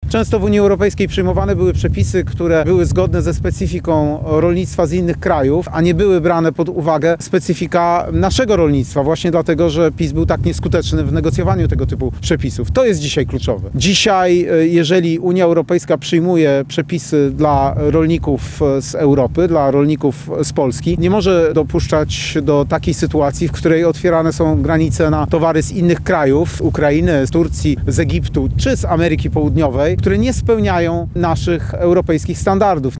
Kandydat na prezydenta Rzeczpospolitej Rafał Trzaskowski spotkał się z dużymi przedsiębiorcami rolnymi w Zosinie. Urzędujący prezydent Warszawy podkreślał, że patriotyzm gospodarczy i efektywna obrona interesów polskich rolników w Unii Europejskiej powinny być priorytetem polskich władz.